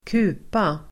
Uttal: [²k'u:pa]